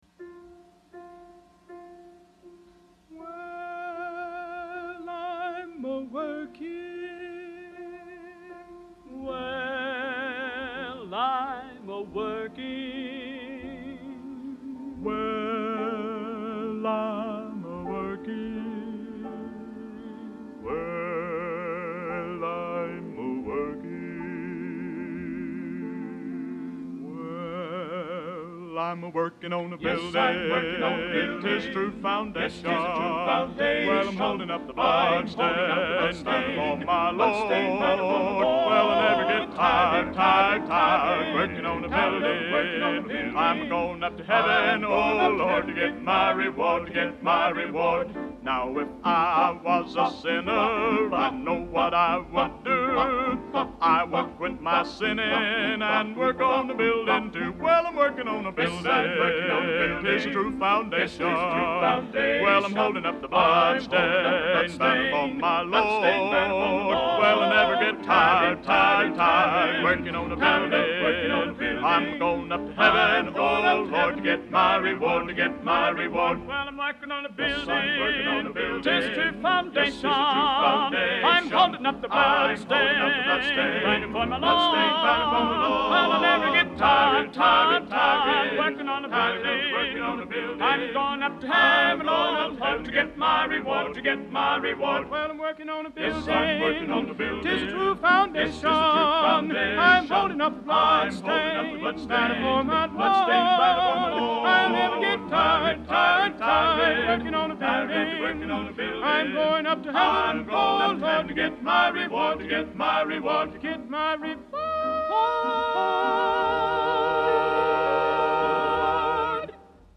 1st Tenor